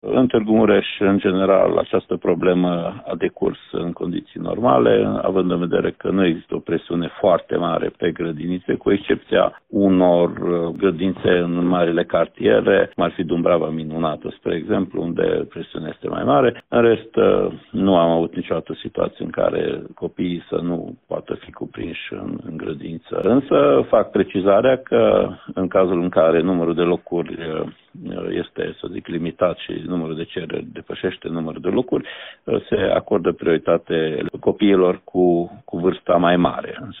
Inspectorul școlar general al județului Mureș, Ștefan Someșan: